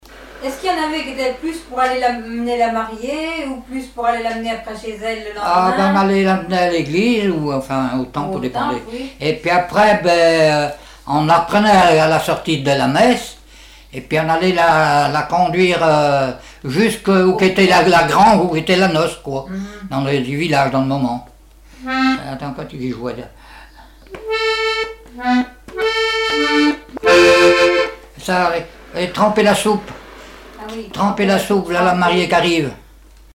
Enquête Arexcpo en Vendée-Association Joyeux Vendéens
accordéon chromatique